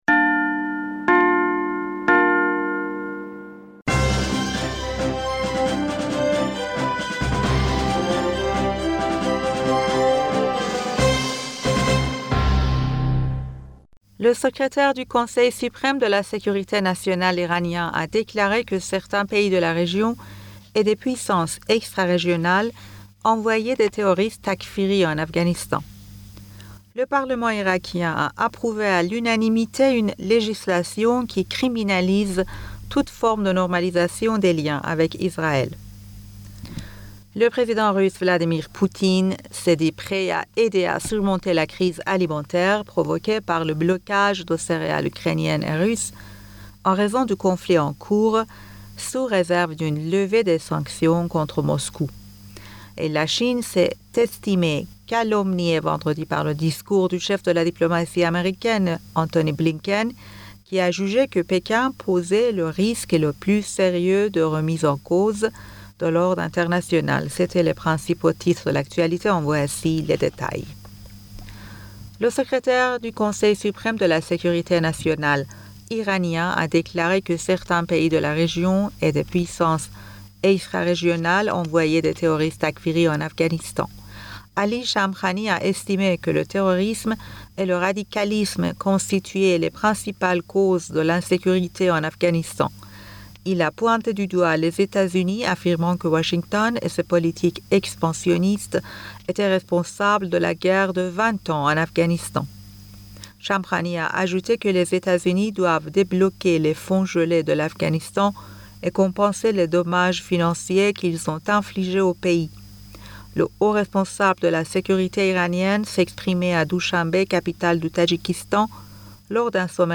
Bulletin d'information Du 27 Mai 2022